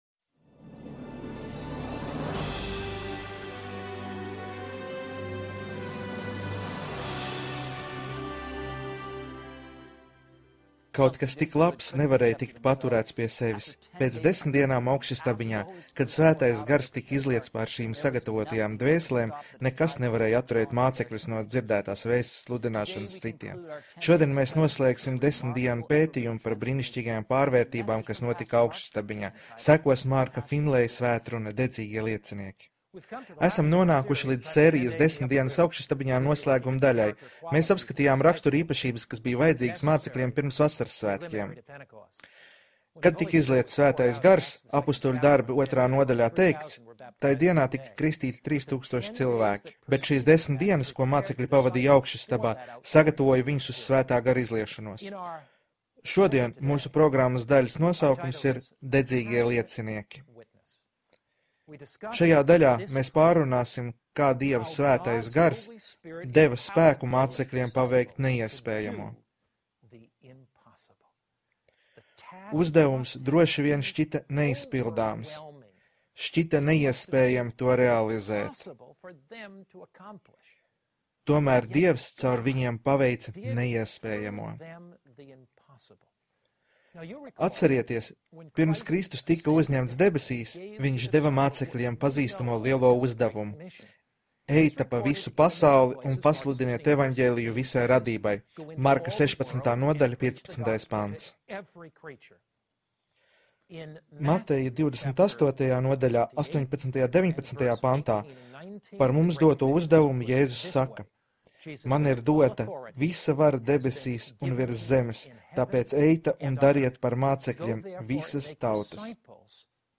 Seminārs
Sludinātājs un evanģēlists.